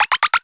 cartoon_boing_climb_run_02.wav